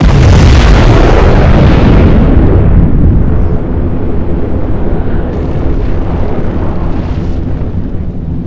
NukeExplode.wav